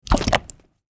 Mud Impact Sound Effect
Description: Mud impact sound effect. Add realistic wet and squishy sounds with mud splat sound effect, capturing mud hitting or splattering on surfaces.
Mud-impact-sound-effect.mp3